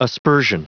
Prononciation du mot aspersion en anglais (fichier audio)
Prononciation du mot : aspersion